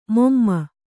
♪ momma